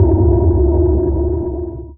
sonarTailSuitCloseShuttle3.ogg